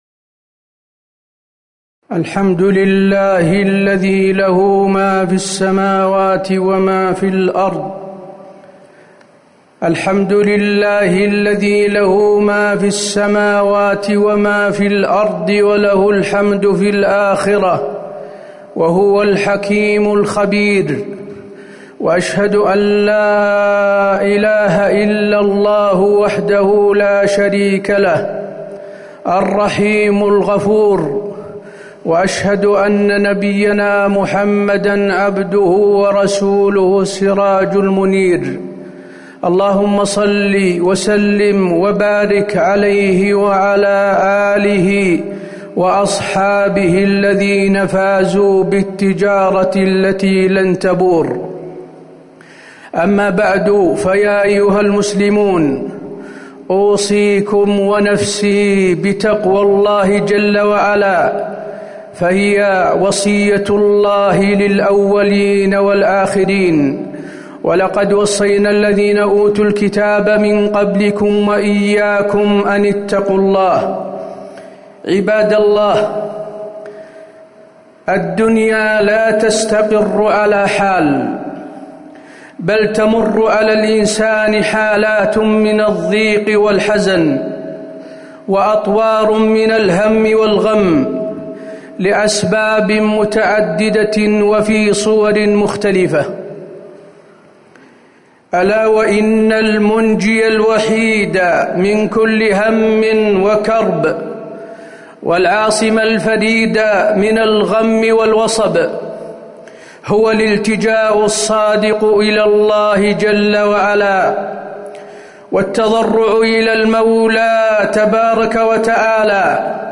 تاريخ النشر ٢٨ محرم ١٤٤١ هـ المكان: المسجد النبوي الشيخ: فضيلة الشيخ د. حسين بن عبدالعزيز آل الشيخ فضيلة الشيخ د. حسين بن عبدالعزيز آل الشيخ الالتجاء إلى الله لدفع الهم والكرب